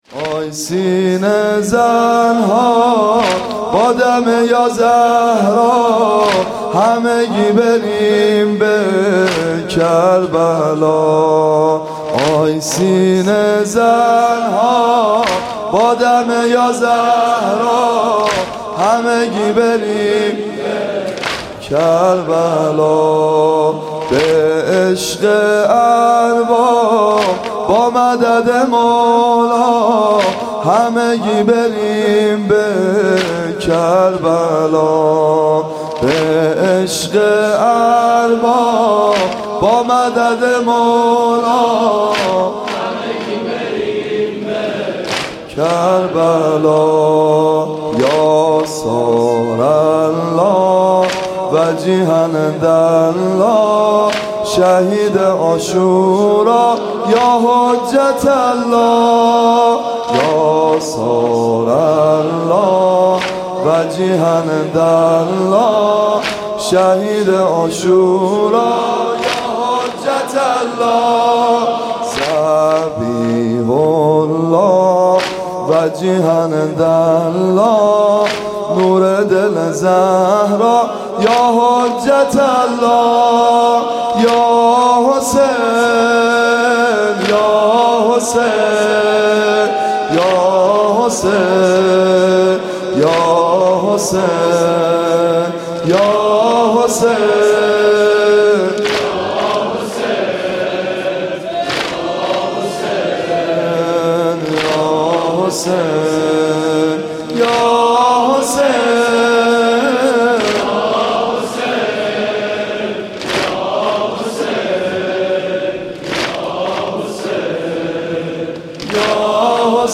شب هفتم محرم الحرام
مداحی